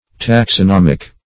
Taxonomic \Tax`o*nom"ic\ (t[a^]ks`[o^]*n[o^]m"[i^]k), a.